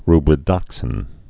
(rbrĭ-dŏksĭn)